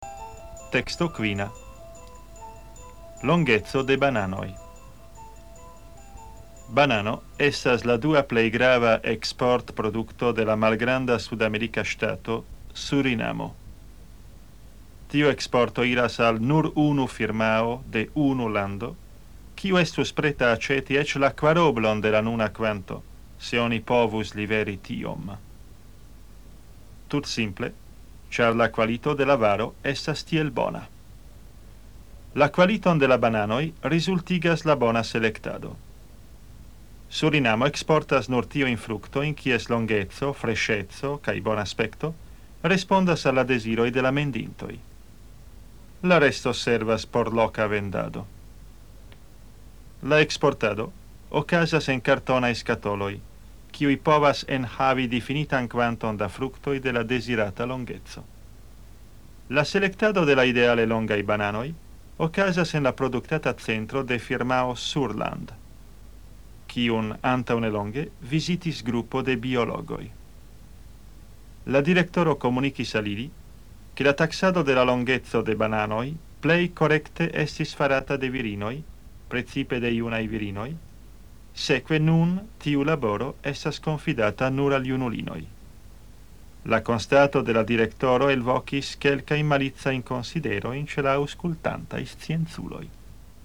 Kategorio: komprena
Temo: Sonmaterialo de E-lingva teksto kun hungarlingvaj kontroldemandoj.